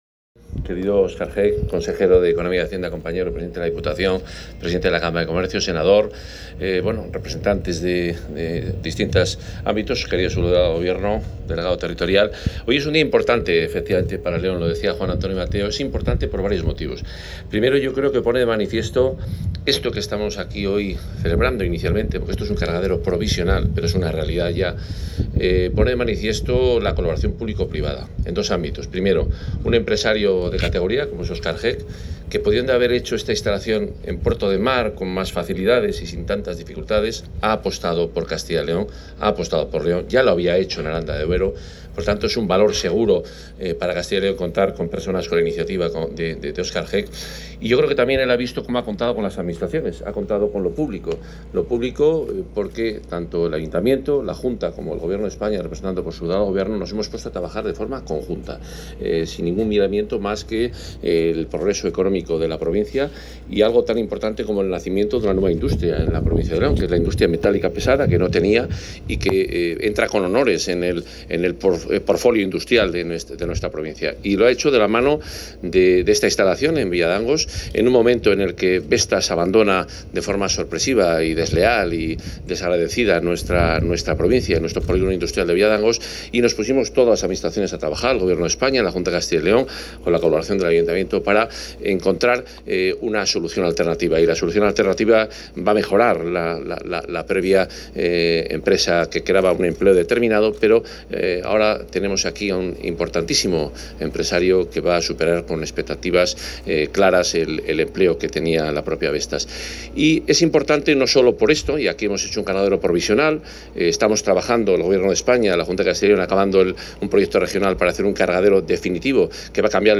Intervención del consejero de Economía y Hacienda.